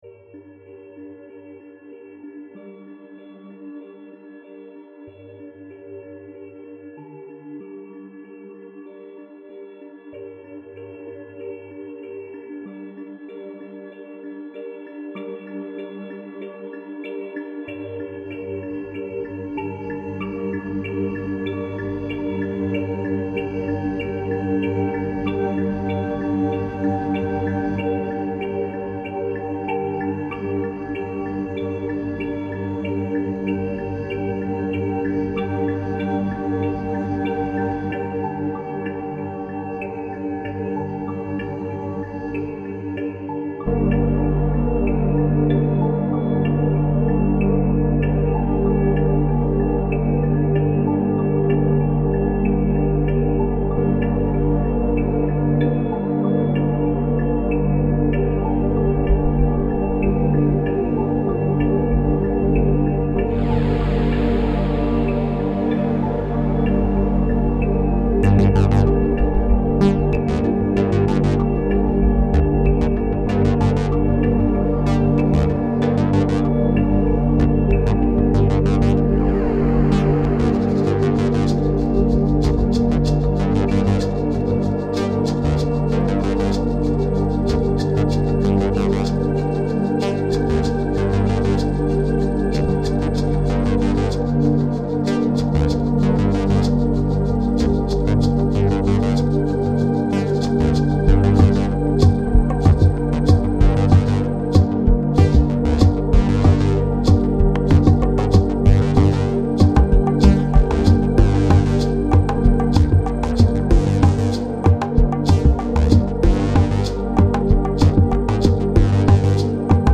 موزیک بی کلام
بی کلام